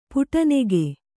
♪ puṭa nege